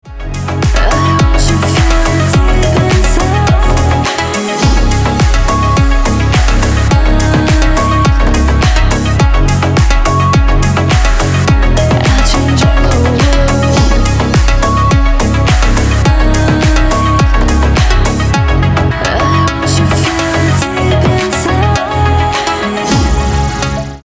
• Качество: 320, Stereo
громкие
мощные
deep house
Midtempo
красивый женский голос